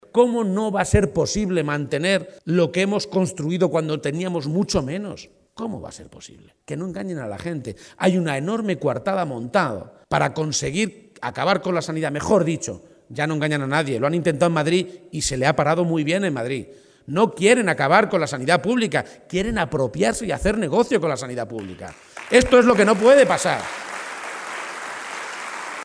“No podemos tolerar que eso quede políticamente impune”, ha afirmado durante un acto de campaña en la localidad de Marchamalo (Guadalajara), en la que ha sido necesario cambiar de ubicación ante la masiva afluencia de militantes y simpatizantes socialistas.